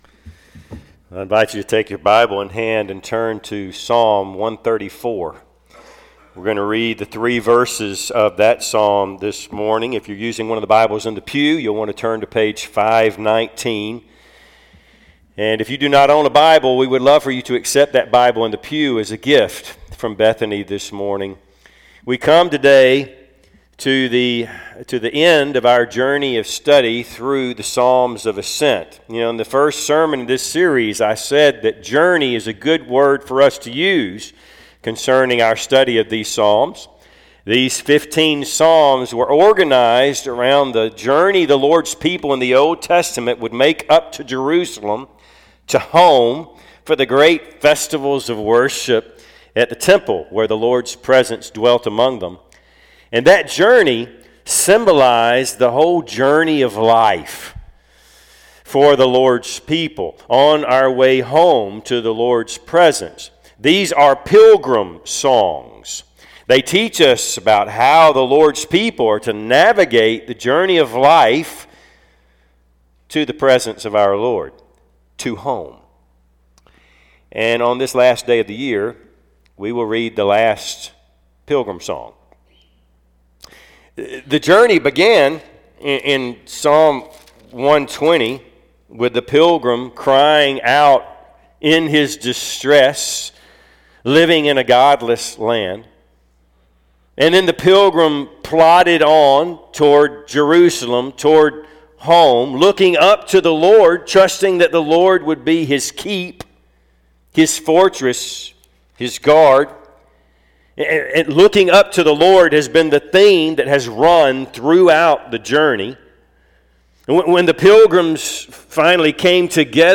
Passage: Psalm 134:1-3 Service Type: Sunday AM